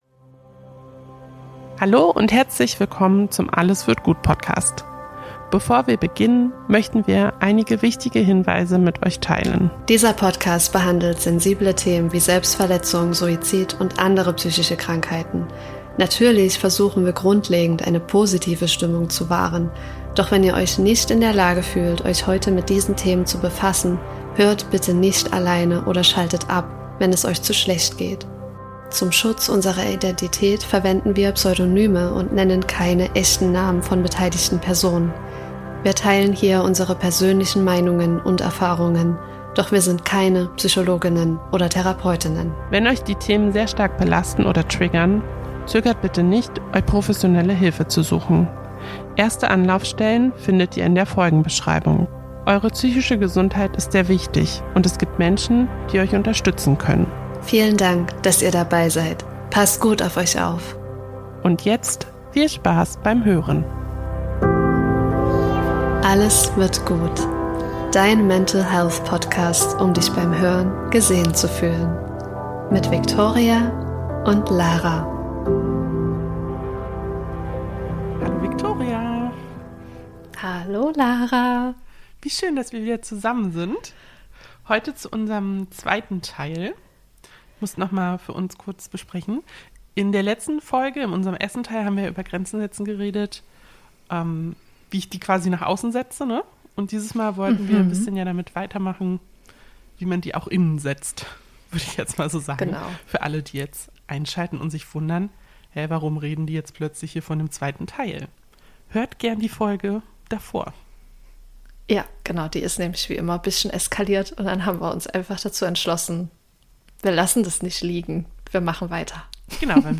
Hund entschieden, sich mit absoluter Hingabe eine Bude im Hundekorb zu bauen – und dabei so engagiert gescharrt, als würde sie ein zweites Wohnzimmer einrichten. Das hört man leider – aber hey: Selbst Tiere wissen, wann sie sich zurückziehen und sich ihren Raum nehmen wollen.